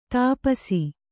♪ tāpasi